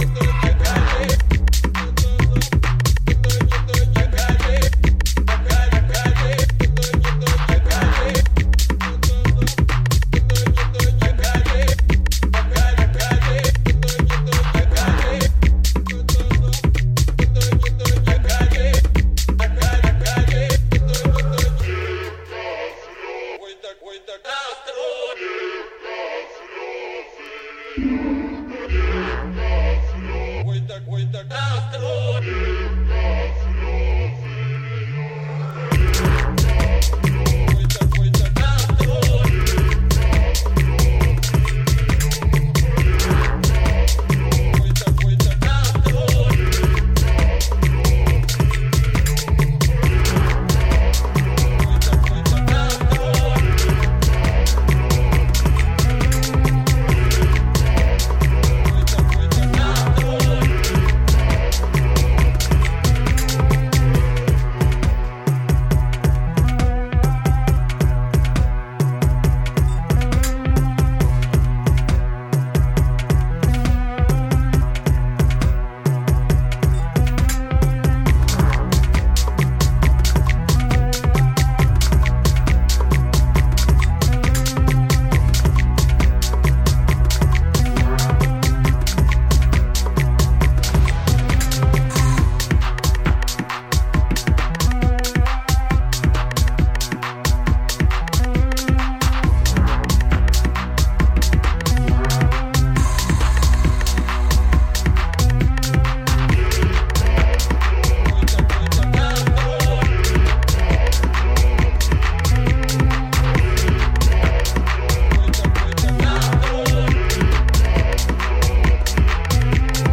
supplier of essential dance music
Electro Electronix House Techno